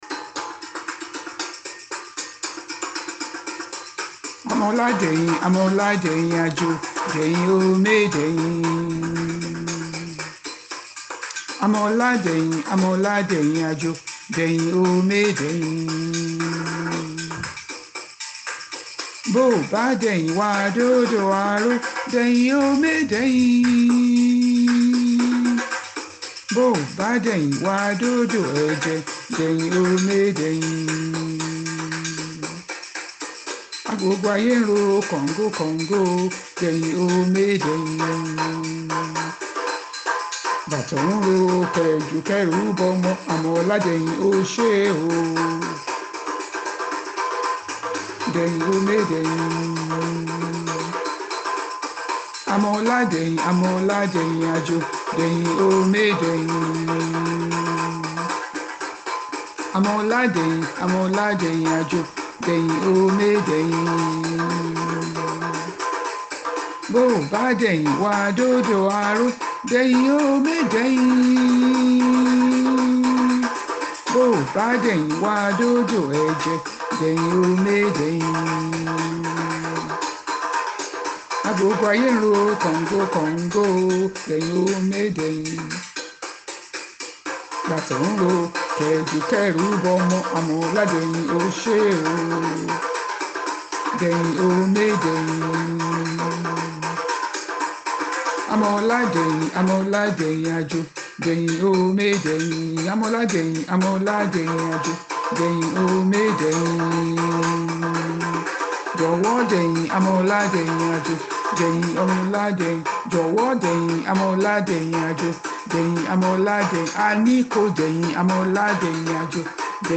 That was when Singbade started to sing.